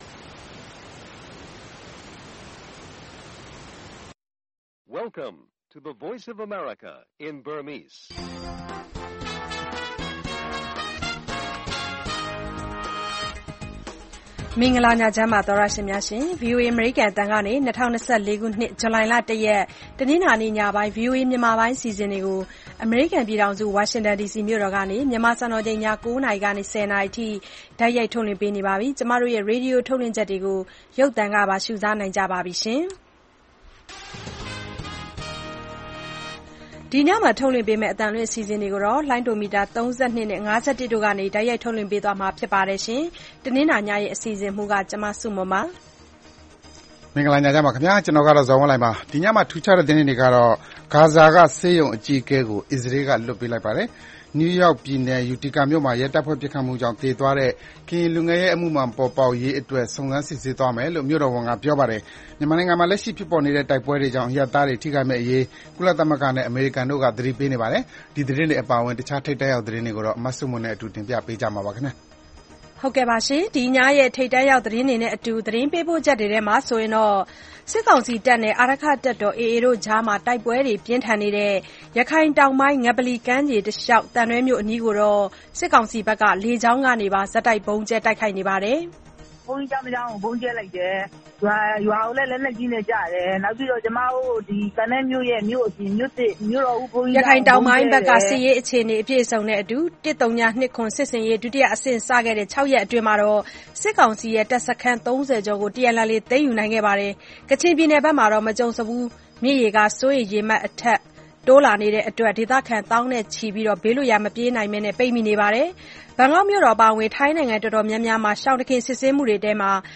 သံတွဲ၊ ငပလီ တိုက်ပွဲတွေကြောင့် ဒေသခံတွေ ဘေးလွတ်ရာတိမ်းရှောင်၊ စစ်ကောင်စီနဲ့ TNLA အဖွဲ့ကြား တိုက်ပွဲဆက်လက် ပြင်းထန်၊ NUG လူ့အခွင့်အရေးရာ ဝန်ကြီး ဦးအောင်မျိုးမင်းနဲ့ ဗွီအိုအေ မေးမြန်းခန်း စတာတွေအပြင် စီးပွားရေး၊ လူမှုရှုခင်း သီတင်းပတ်စဉ် အစီအစဉ်တွေကို တင်ဆက်ထားပါတယ်။